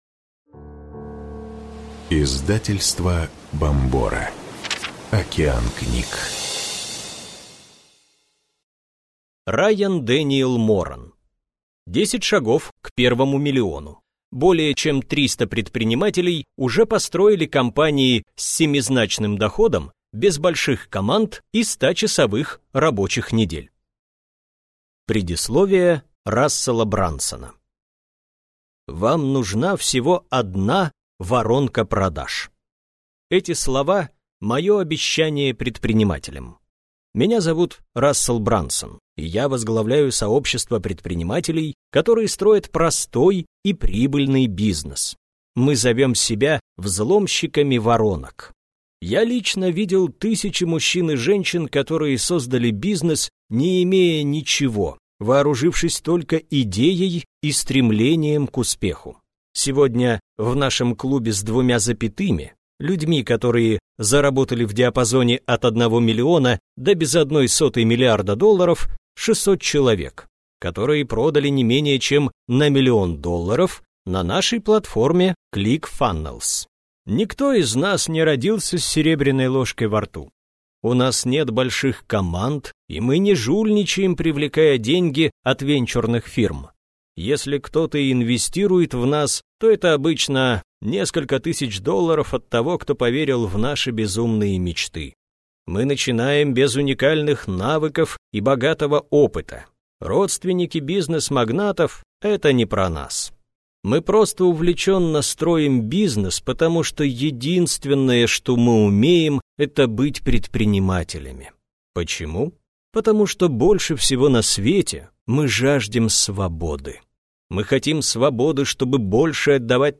Аудиокнига 10 шагов к первому миллиону.